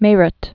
(mārət, mîrət)